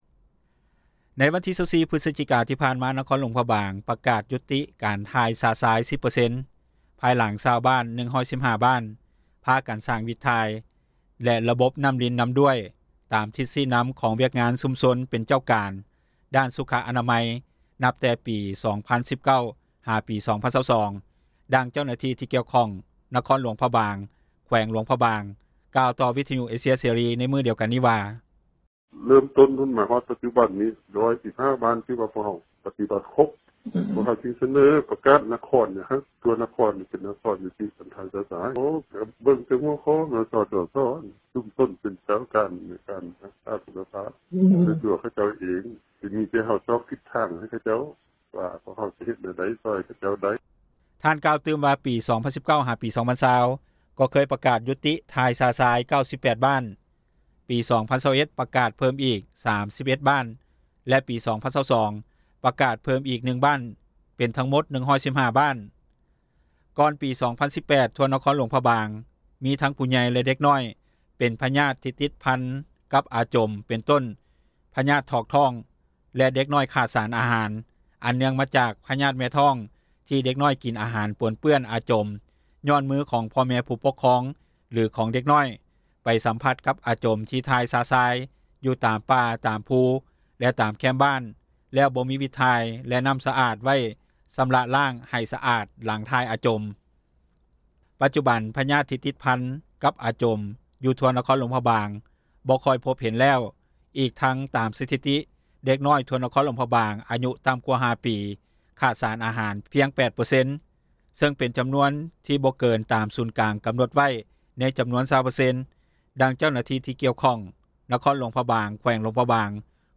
ນັກຂ່າວ ພົລເມືອງ